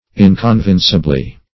Search Result for " inconvincibly" : The Collaborative International Dictionary of English v.0.48: Inconvincibly \In`con*vin"ci*bly\, adv. In a manner not admitting of being convinced.